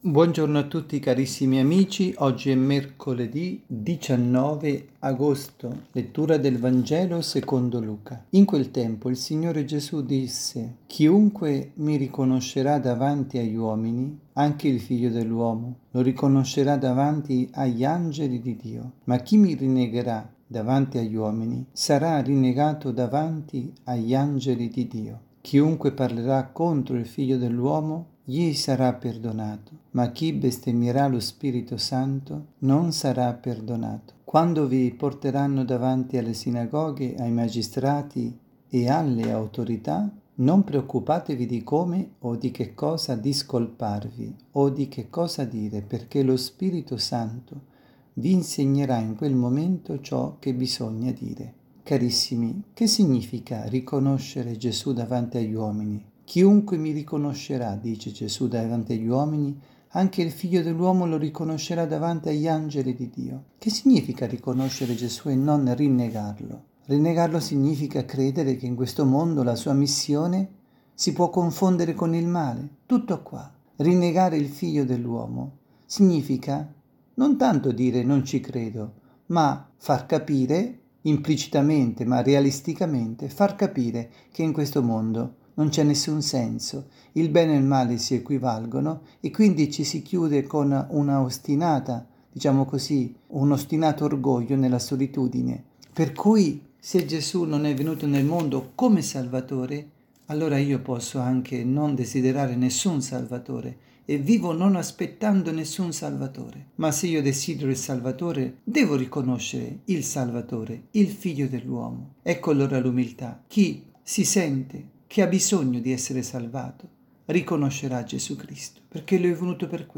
avvisi, Omelie
Catechesi
dalla Parrocchia S. Rita – Milano